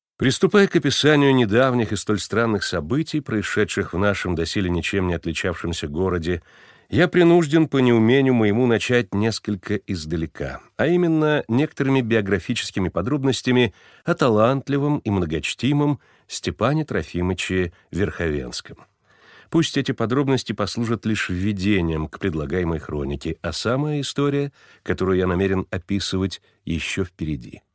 Всеволод Кузнецов — Заказать озвучку голосом профессионального диктора и актёра дубляжа
Всеволод Борисович Кузнецов потрясающий мастер дубляжа, актёр озвучивания с гигантским опытом, профессиональный звукорежиссер.